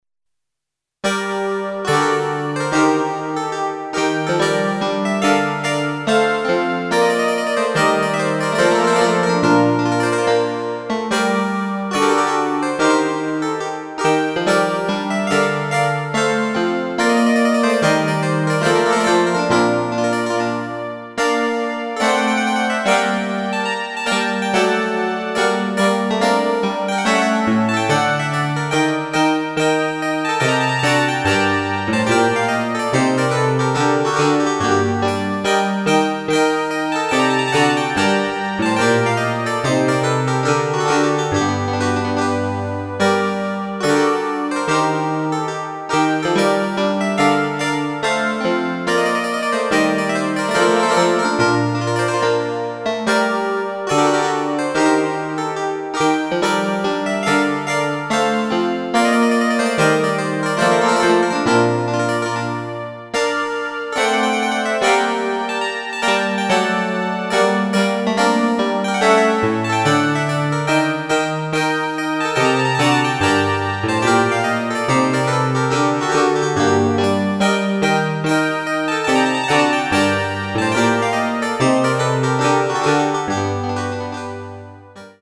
harpsichord: Lully
clav.mp3